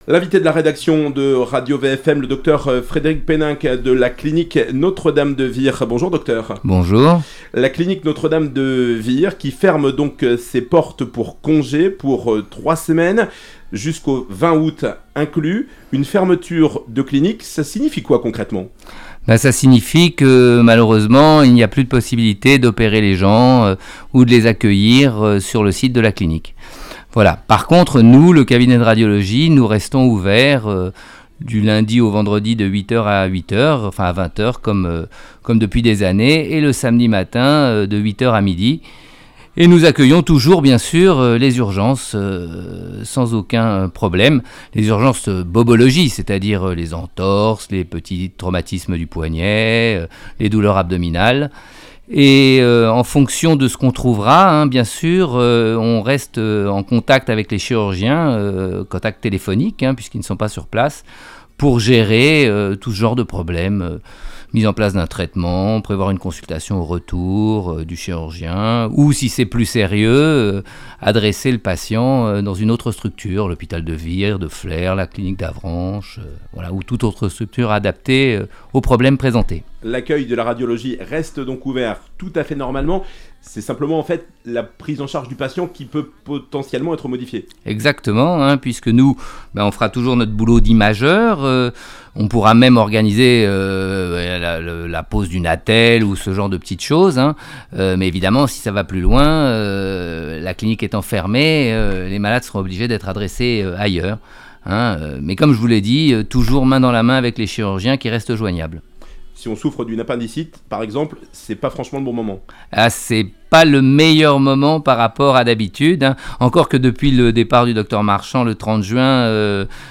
Interview RadioVFM